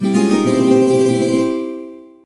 poco_ulti_sfx.ogg